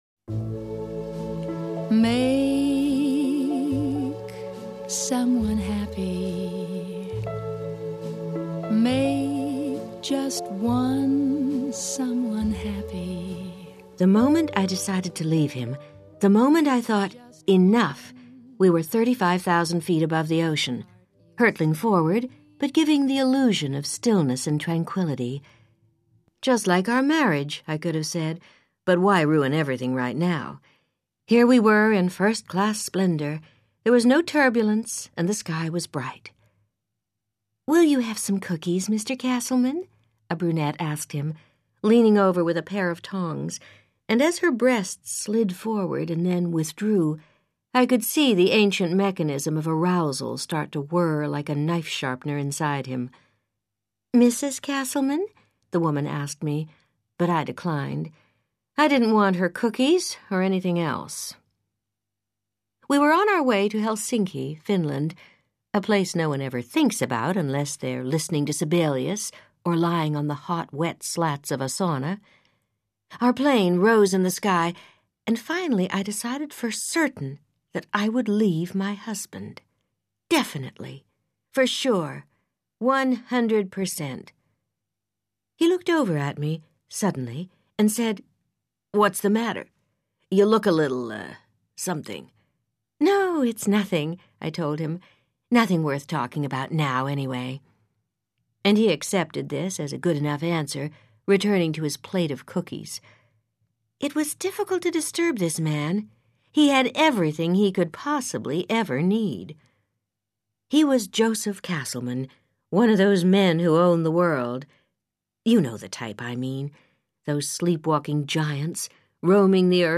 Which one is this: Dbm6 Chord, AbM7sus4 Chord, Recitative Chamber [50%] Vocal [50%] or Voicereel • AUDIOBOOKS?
Voicereel • AUDIOBOOKS